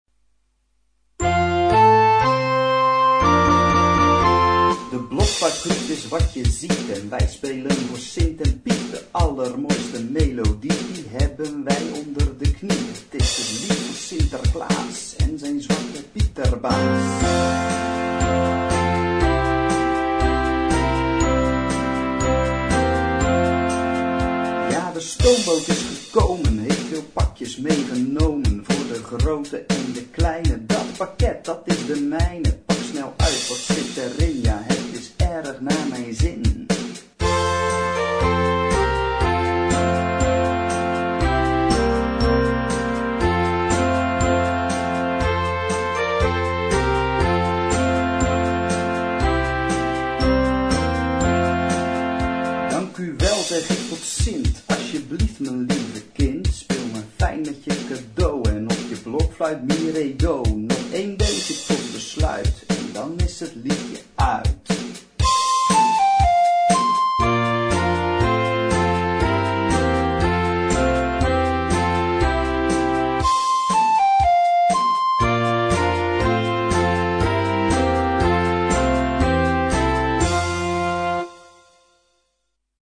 Für Blockflöte & Young Band.
Noten für flexibles Ensemble, 4-stimmig + Percussion.